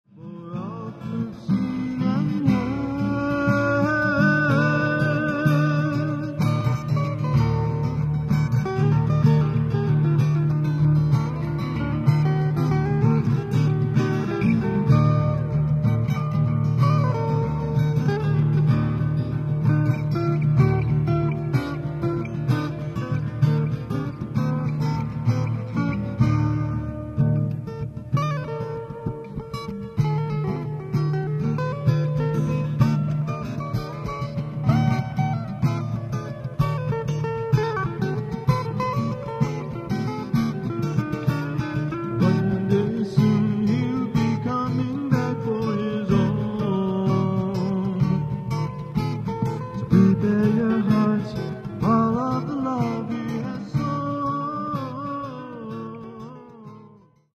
Worship Songs: